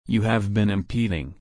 /ɪmˈpiːd/